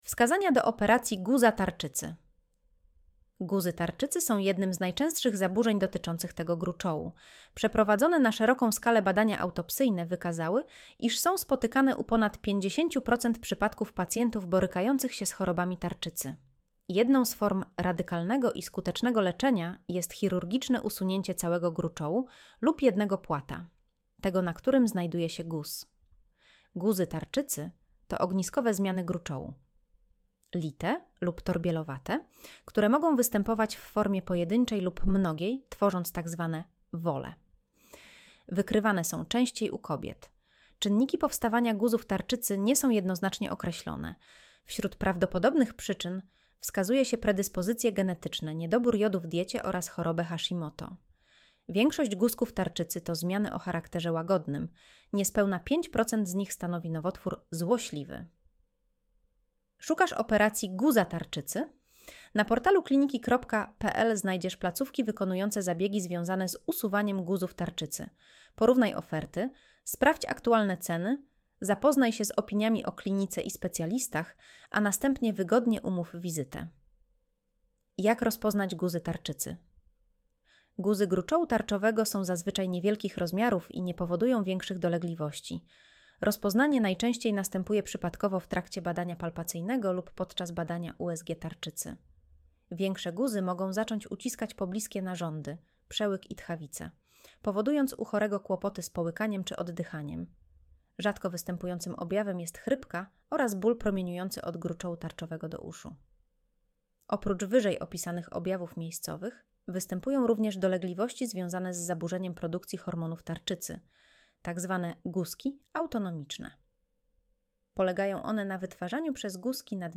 Posłuchaj (06:04 min) Streść artykuł Słuchaj artykułu Audio wygenerowane przez AI, może zawierać błędy 00:00 / 0:00 Streszczenie artykułu (AI): Streszczenie wygenerowane przez AI, może zawierać błędy Spis treści Jak rozpoznać guzy tarczycy?